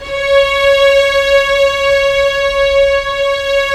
Index of /90_sSampleCDs/Roland - String Master Series/STR_Vlns 1 Symph/STR_Vls1 Sym slo